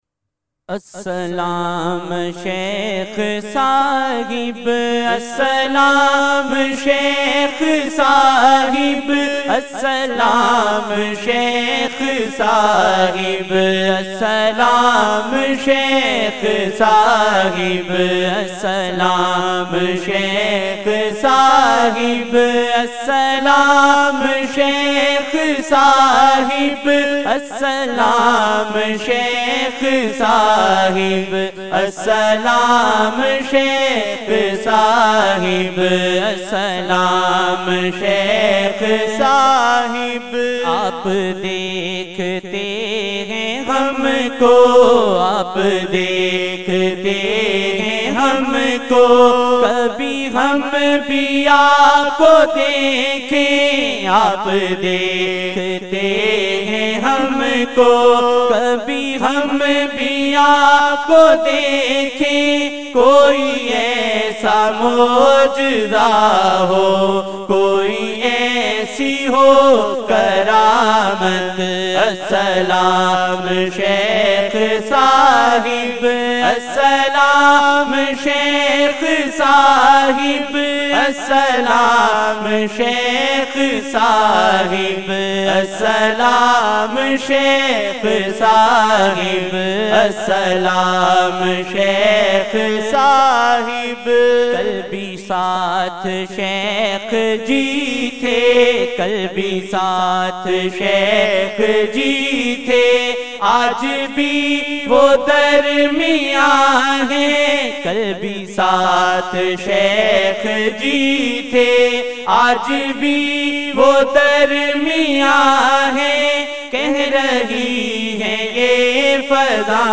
Assalam Shaikh Sahib Assalam Shaikh Sahib Aap Dekhte Hain Humko Kabhi Ham Bhi Aapko Dekhen 2025-04-19 19 Apr 2025 New Naat Shareef